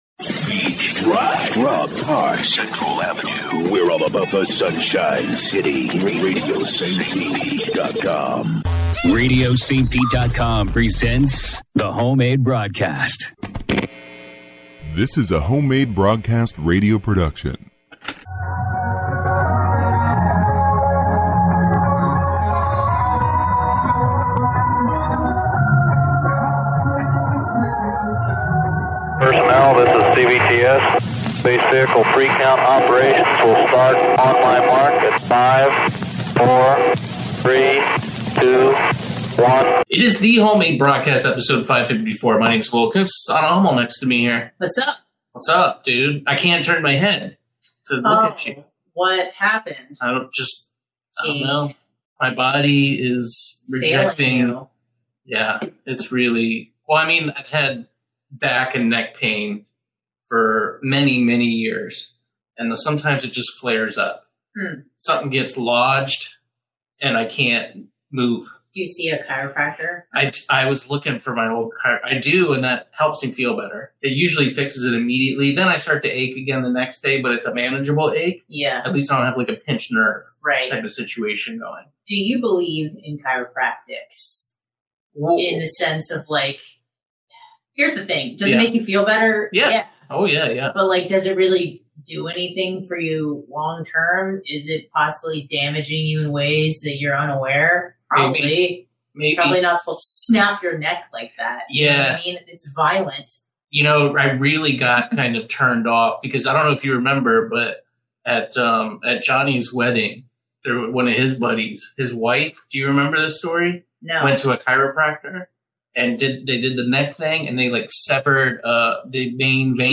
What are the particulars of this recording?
This week we are finally able to take calls. We had some audio issues at the beginning but luckily we did get a call to let us know.